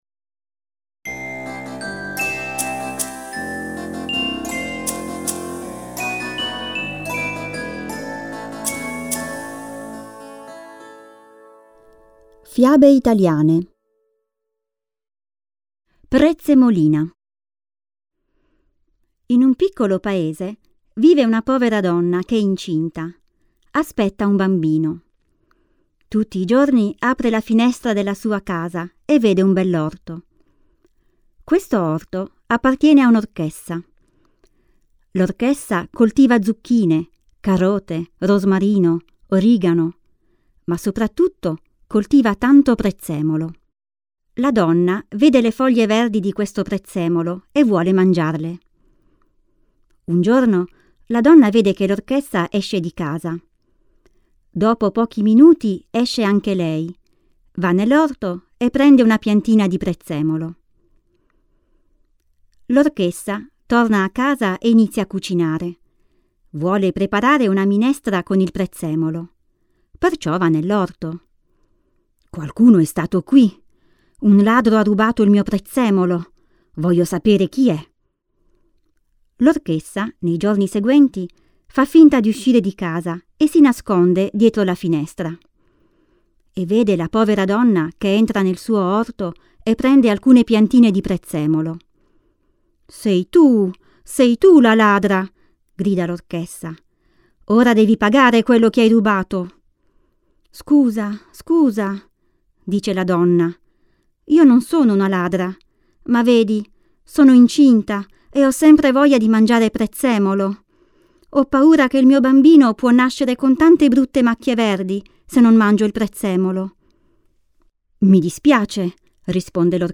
Příběhy jsou namluveny rodilými mluvčími a jsou vhodné pro začátečníky a mírně pokročilé studenty italštiny.
AudioKniha ke stažení, 5 x mp3, délka 56 min., velikost 127,7 MB, italsky